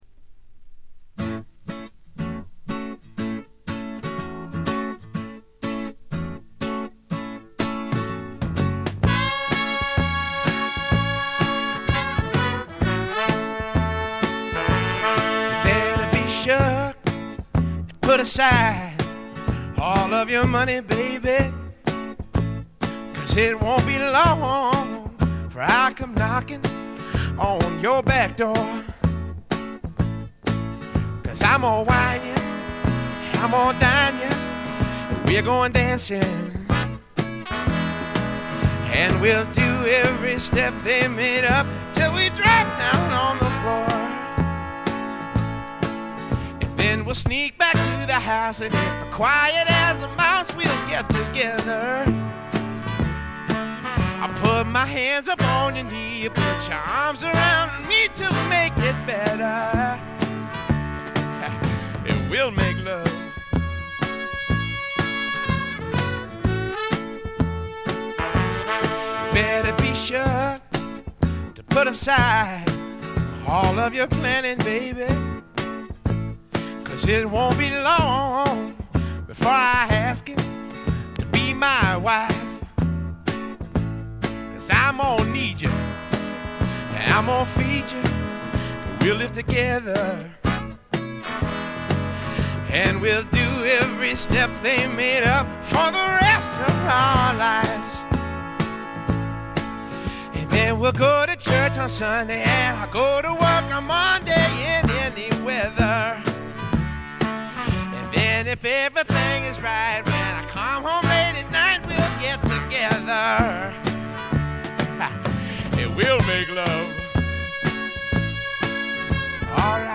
ごくわずかなノイズ感のみ。
試聴曲は現品からの取り込み音源です。